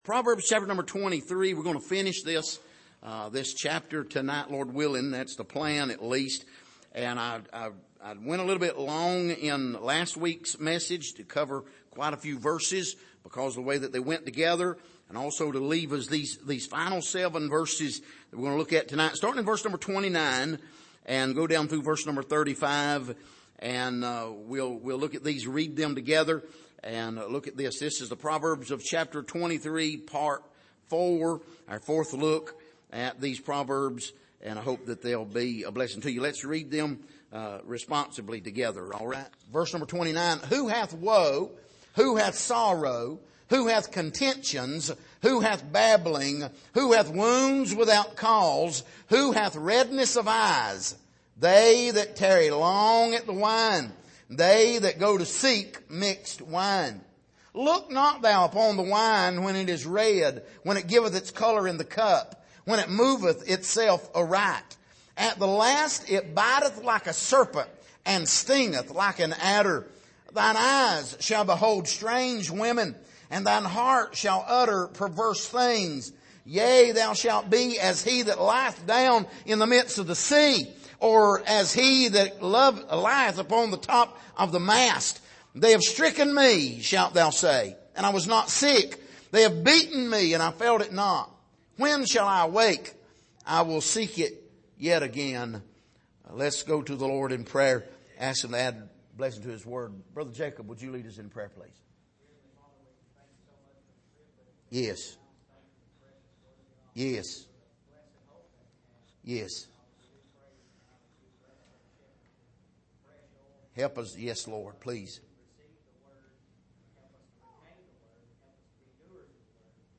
Passage: Proverbs 23:29-35 Service: Sunday Evening